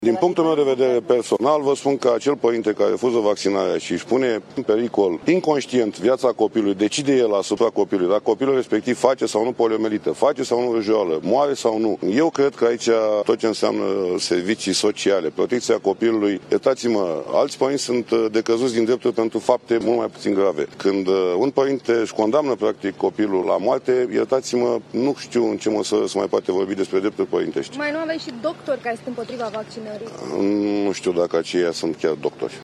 Premierul Mihai Tudose i-a criticat, miercuri, pe părinții care refuză să își vaccineze copiii și a vorbit chiar despre decăderea din drepturile părintești.
26iul-13-Tudose-despre-refuzul-vaccinarii.mp3